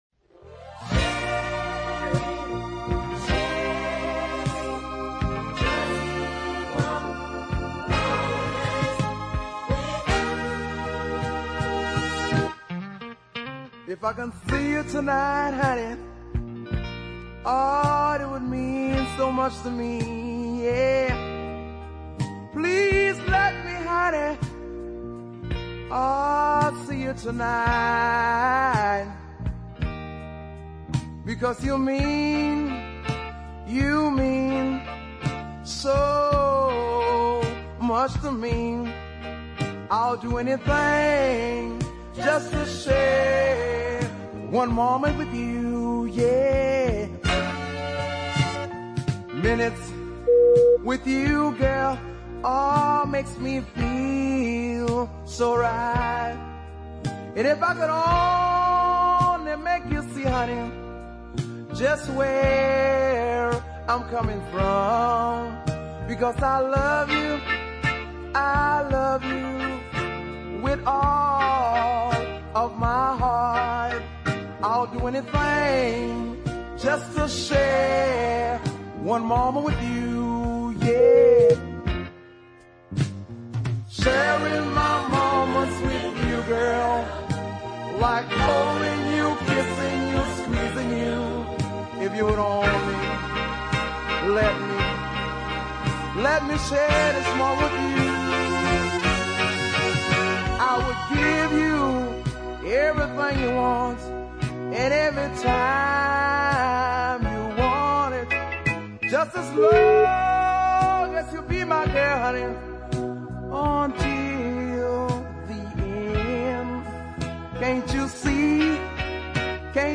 southern soul